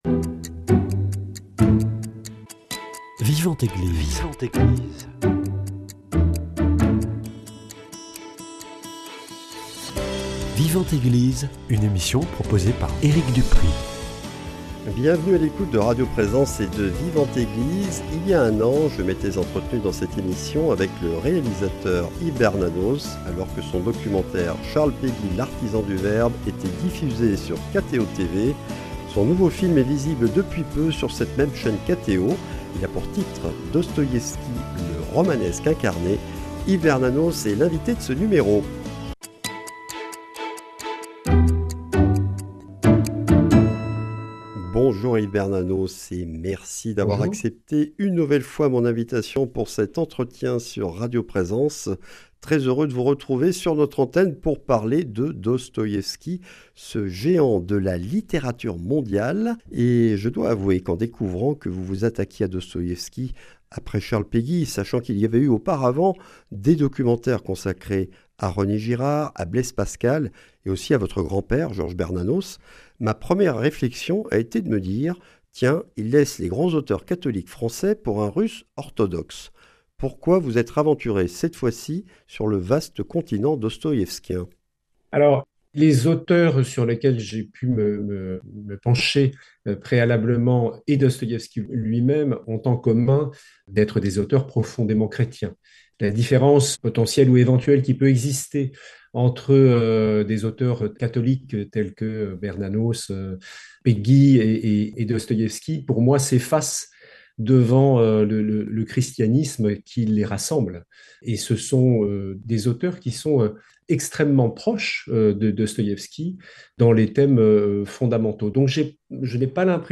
Entretien autour de cette passionnante plongée dans la vie et l’oeuvre d’un des géants de la littérature mondiale.